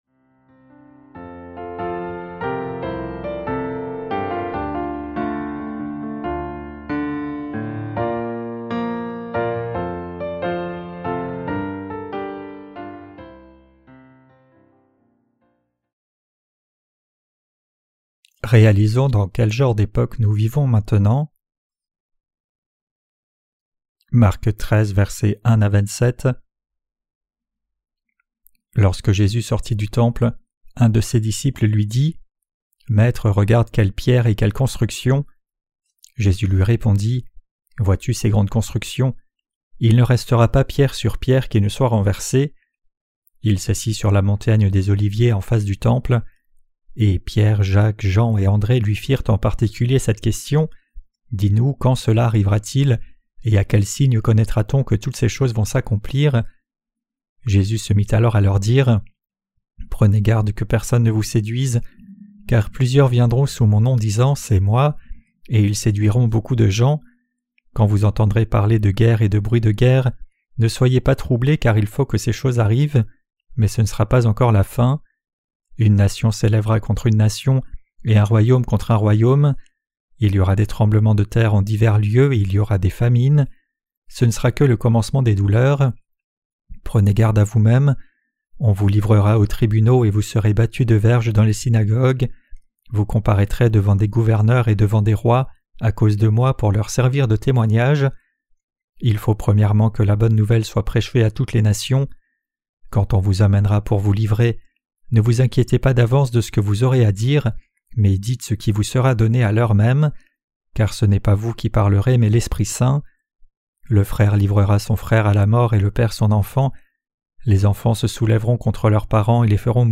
Sermons sur l’Evangile de Marc (Ⅲ) - LA BÉNÉDICTION DE LA FOI REÇUE AVEC LE CŒUR 6.